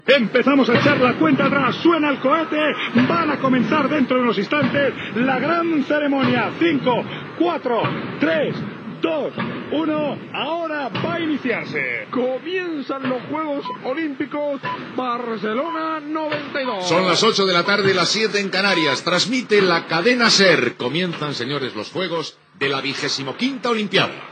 Narració de la cerimònia inaugural dels Jocs Olímpics de Barcelona, des de l'Estadi Olímpic.
Inici de la cerimònia inaugural amb el conte enrera, la identificació de la cadena i l'hora.
Esportiu